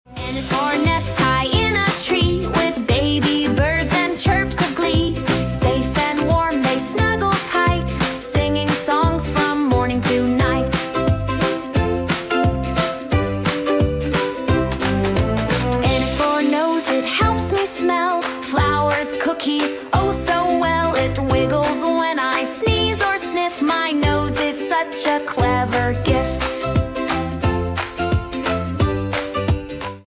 ABC Song for Kids
This catchy children’s song